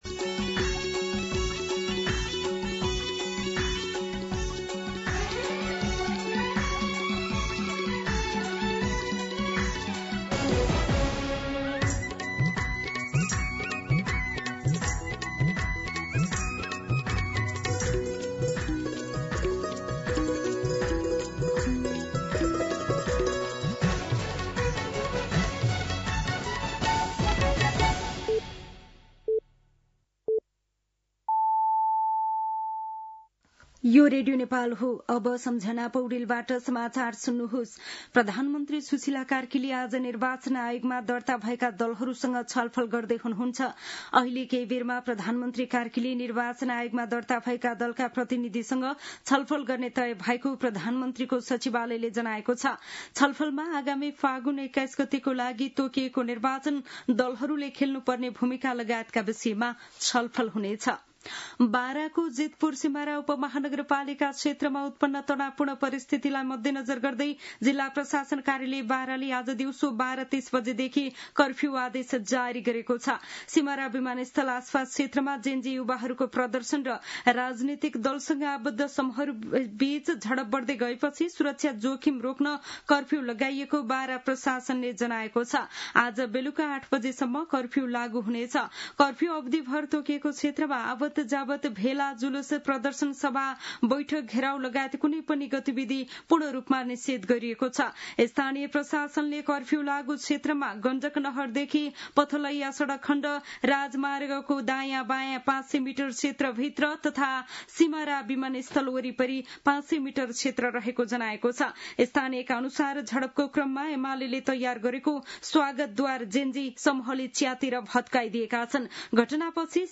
दिउँसो ४ बजेको नेपाली समाचार : ३ मंसिर , २०८२
4-pm-Nepali-News-3.mp3